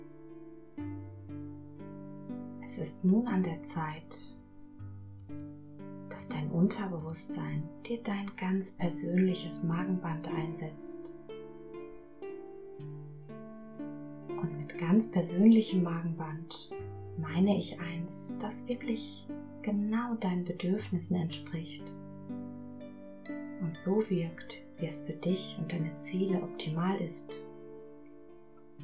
Hörprobe: G2004 – Erstsitzung Hypnotisches Magenband (weibliche Stimme)
G2004-Erstsitzung-Hypnotisches-Magenband-weibliche-Stimme-Hoerprobe.mp3